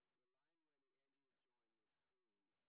sp22_street_snr20.wav